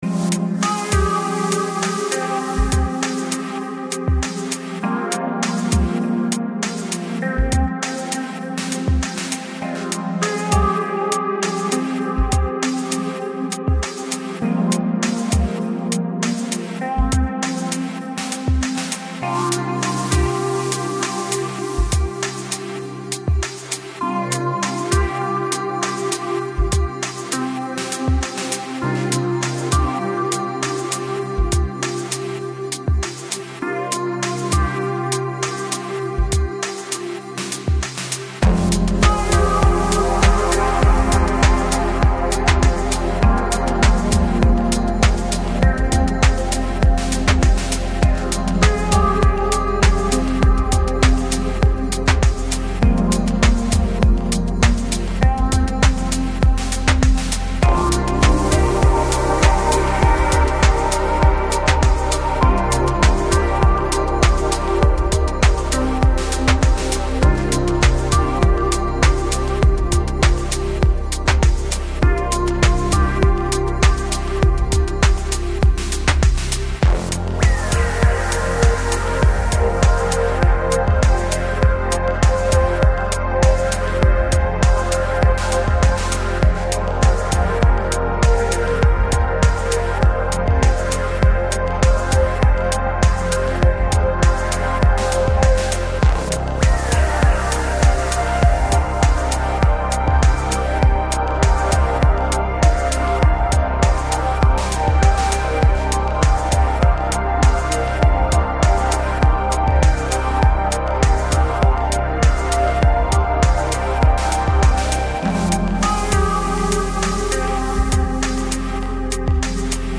Жанр: Lo-Fi, Lounge, Chillout